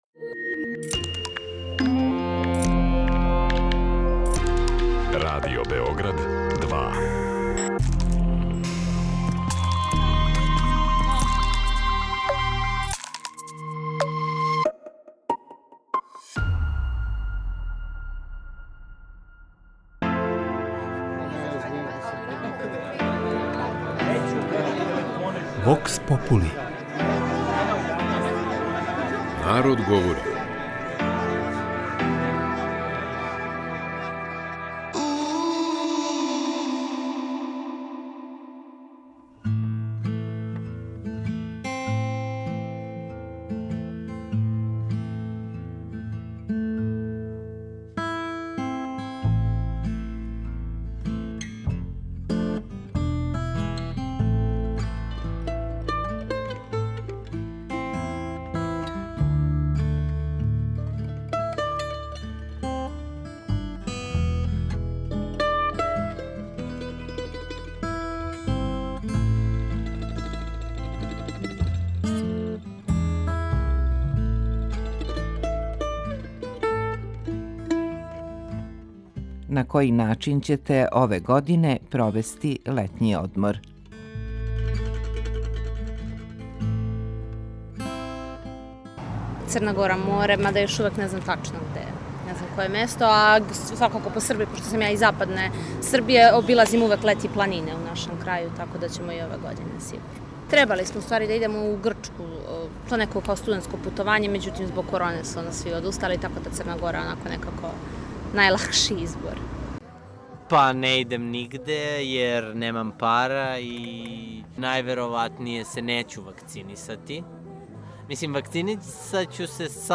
кроз кратке монологе, анкете и говорне сегменте у којима ће случајно одабрани, занимљиви саговорници одговарати на питања